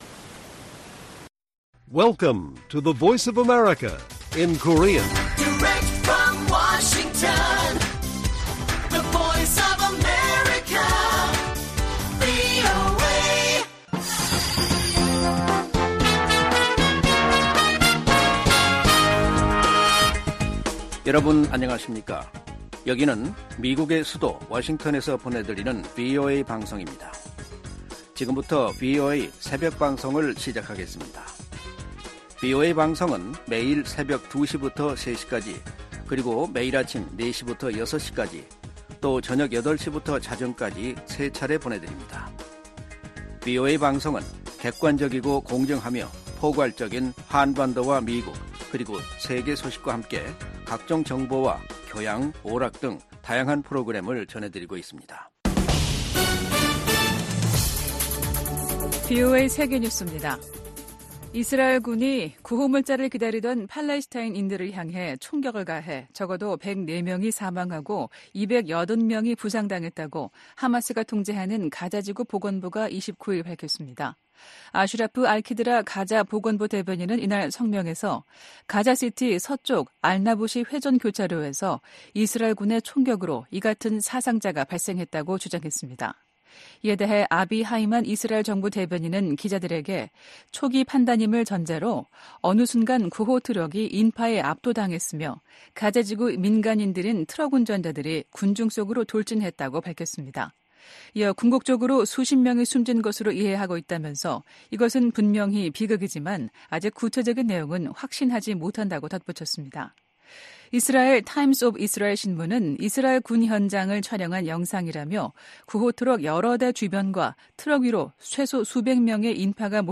VOA 한국어 '출발 뉴스 쇼', 2024년 3월 1일 방송입니다. 미국과 한국의 외교장관들이 워싱턴 D.C.에서 만나 세계의 거의 모든 도전에 공조하는 등 양국 협력이 어느 때보다 강력하다고 평가했습니다. 북한이 유엔 군축회의에서 국방력 강화 조치는 자위권 차원이라며 비난의 화살을 미국과 동맹에게 돌렸습니다. 세계 최대 식품 유통업체가 북한 강제 노동 동원 의혹을 받고 있는 중국 수산물 가공 업체 거래를 전격 중단했습니다.